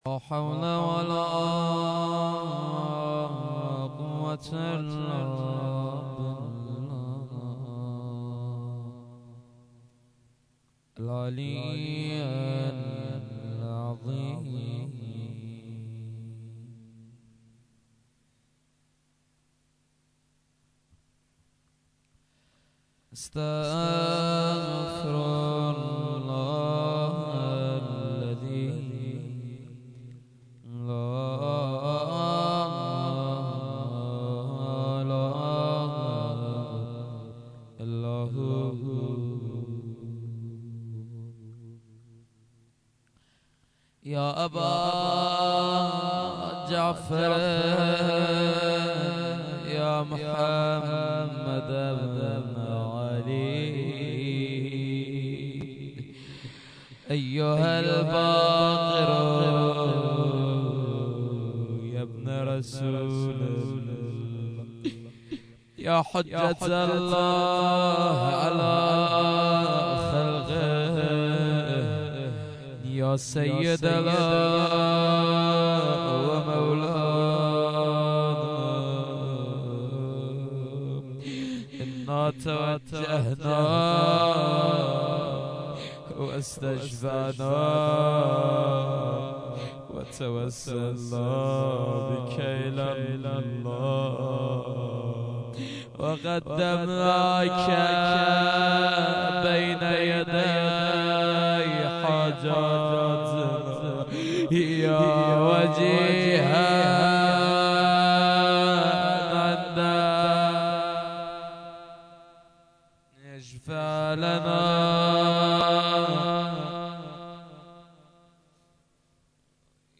shahadat-emam-bagher-93-rozeh-2.mp3